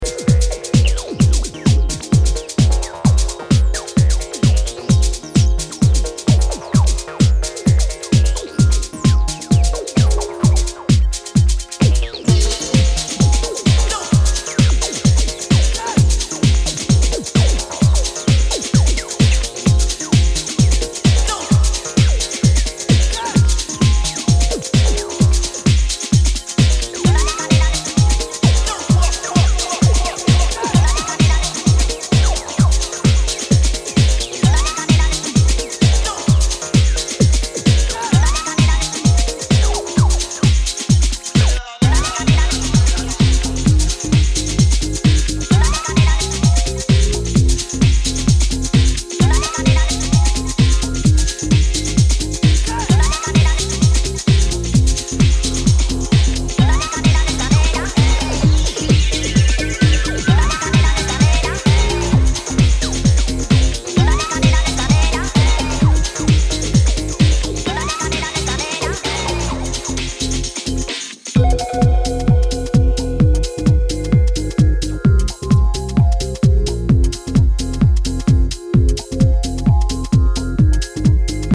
4 dancefloor burners